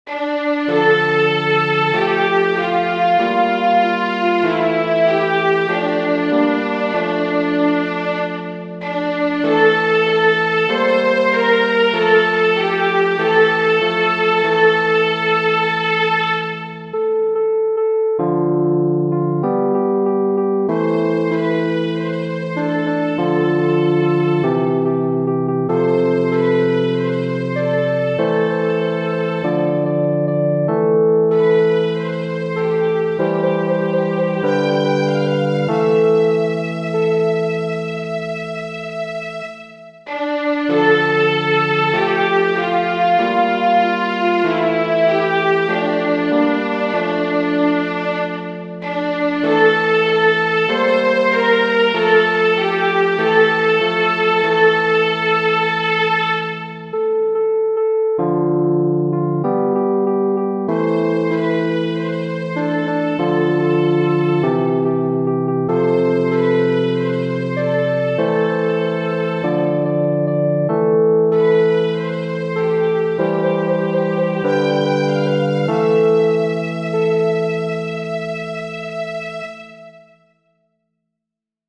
Gelineau, J. Genere: Religiose Testo: Salmo 50 RIT.
"Purificami o Signore" è un canto liturgico cattolico del 1953, composto dal sacerdote francese Joseph Gelineau su testo tratto dal Salmo 50.